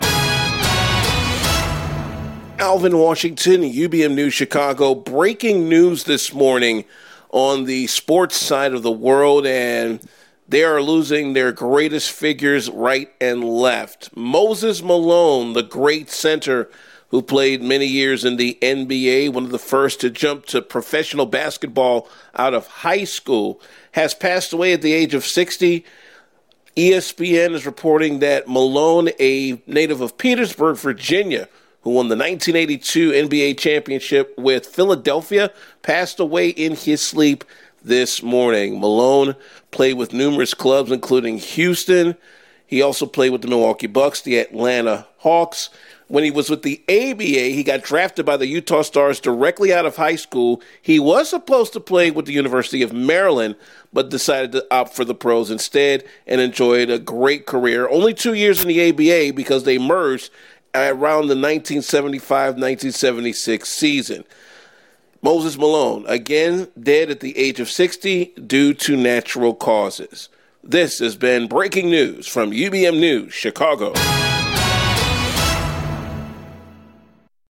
UBM BREAKING NEWS - MOSES MALONE DIES